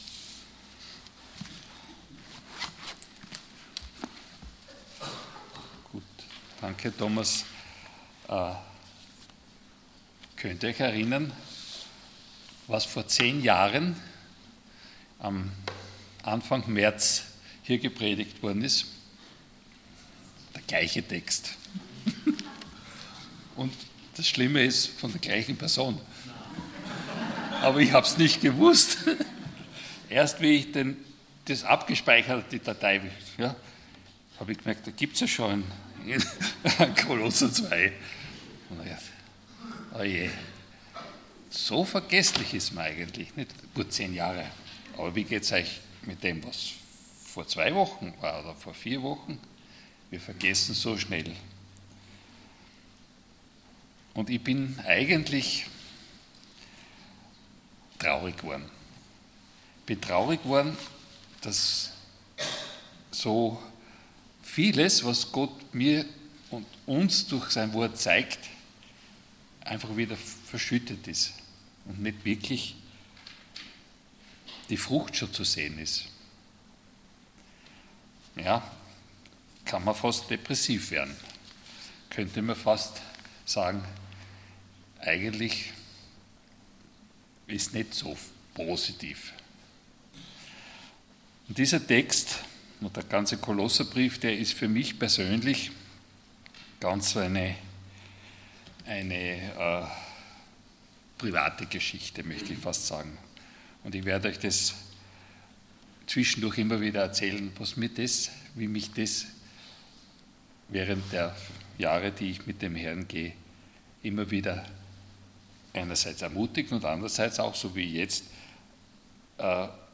Passage: Kolosser 2,8-15 Dienstart: Sonntag Morgen